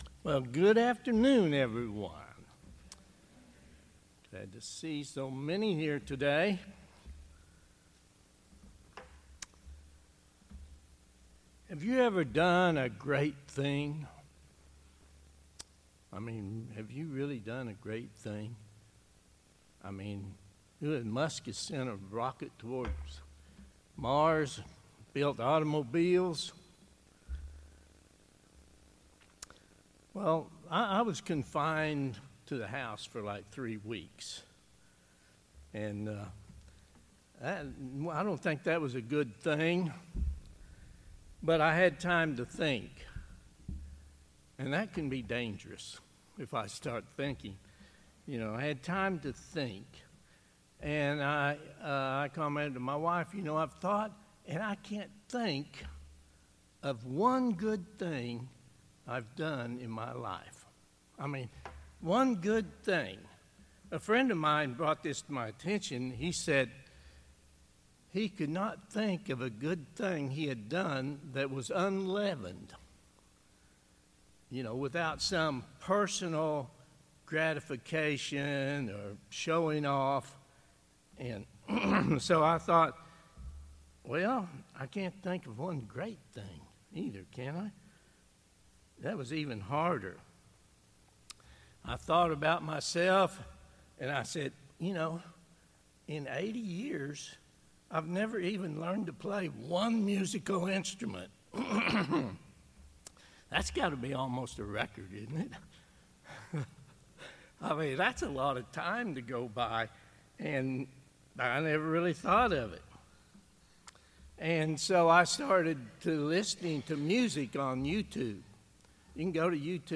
Given in Oklahoma City, OK